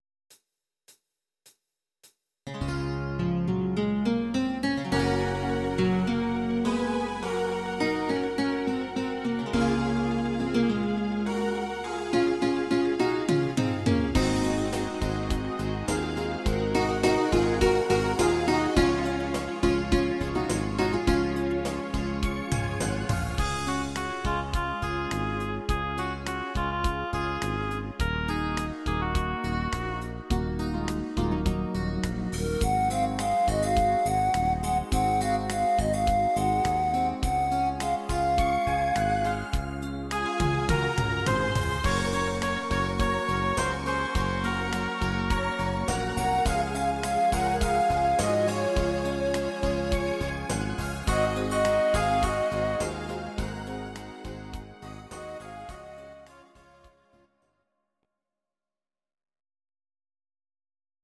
Audio Recordings based on Midi-files
German, Duets, 1990s